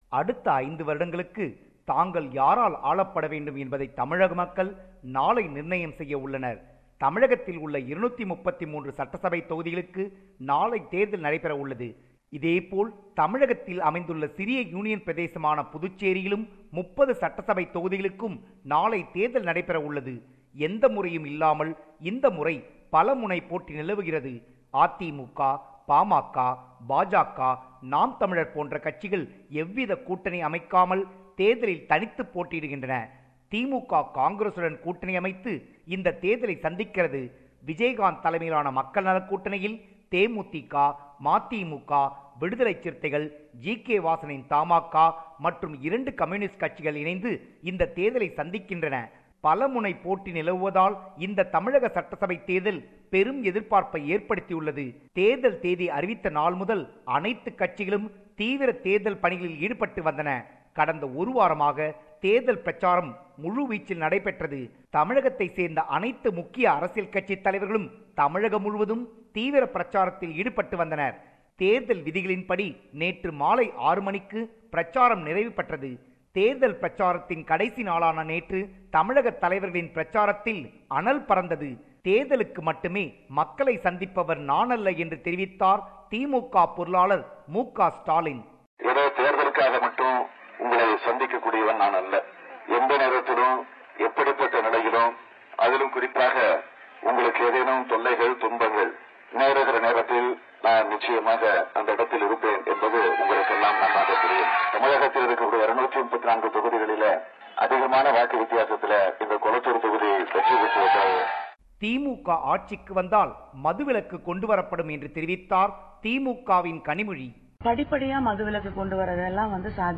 compiled this report.